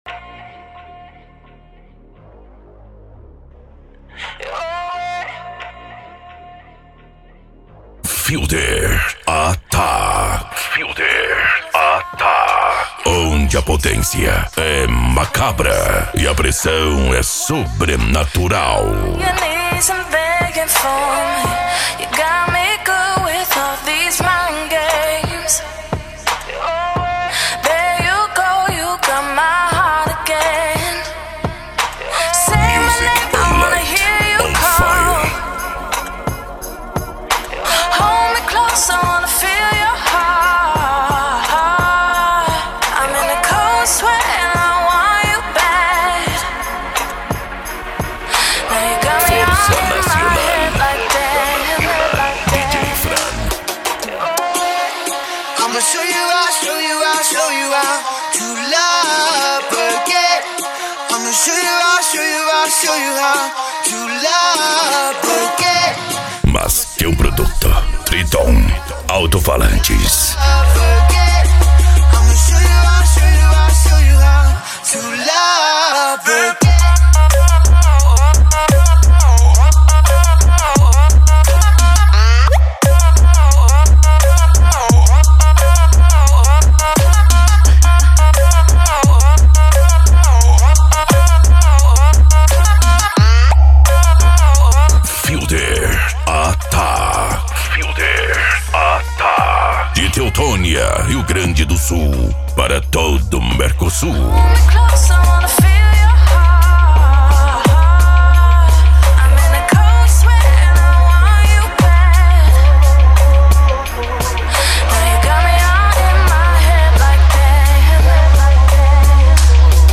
Bass
PANCADÃO